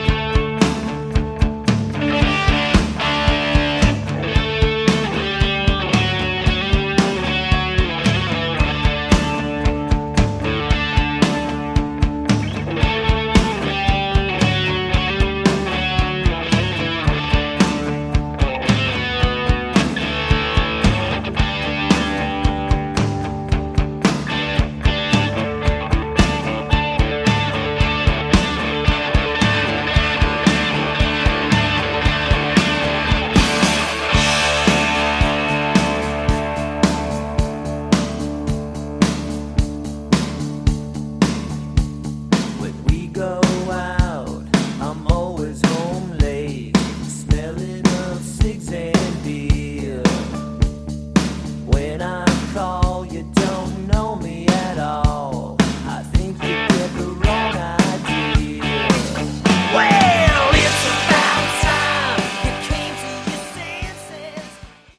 Category: Sleaze Glam
lead vocals, harmonica
guitar, vocals
lead guitar, vocals
bass, vocals
drums, percussion